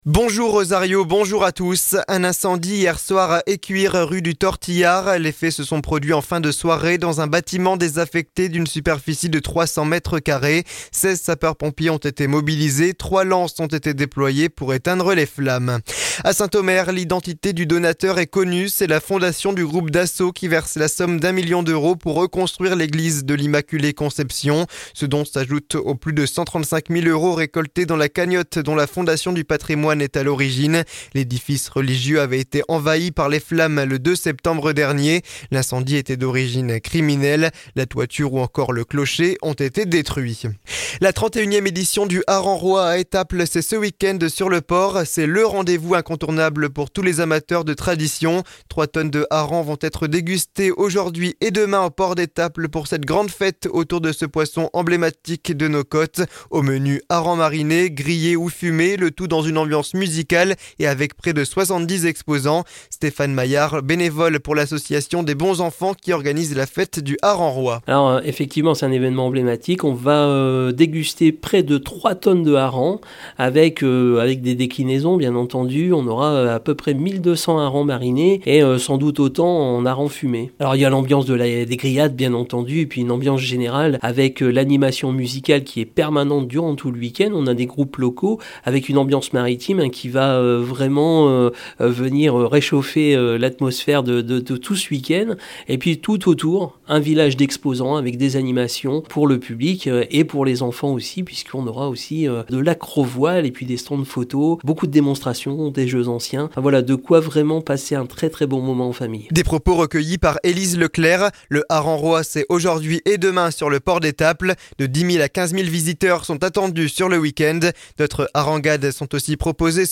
Le journal du samedi 16 novembre